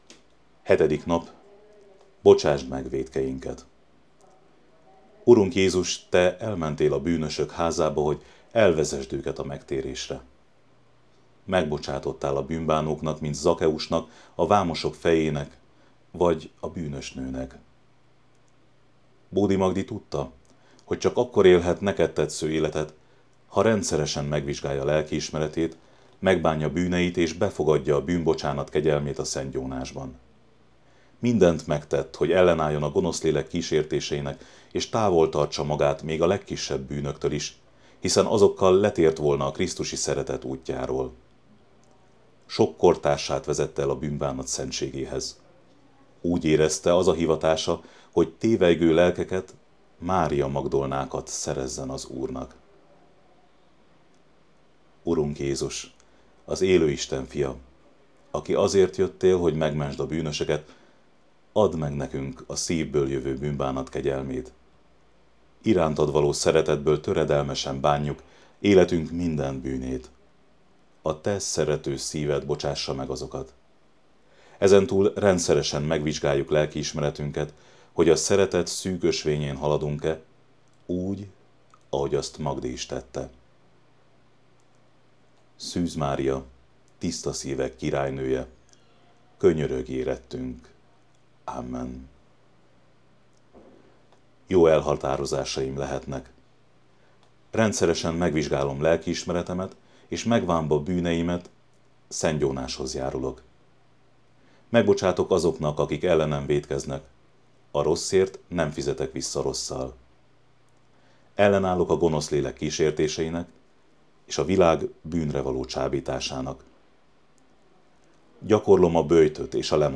atya felolvasásában: